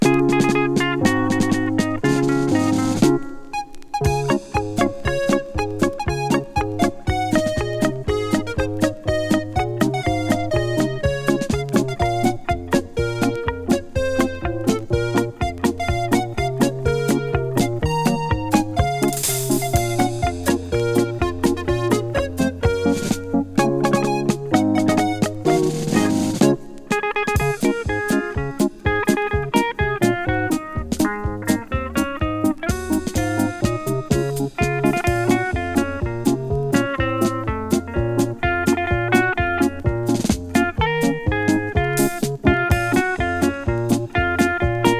Jazz, Pop, Easy Listening　Canada　12inchレコード　33rpm　Stereo